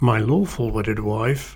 But on this occasion the speaker said (with a smile) ‘my lawful wedded wife’, rather like:
So the pronunciation ‘my lawful wedded wife’ tells us that the person in question was already a wedded wife, and it was only the lawfulness that was new.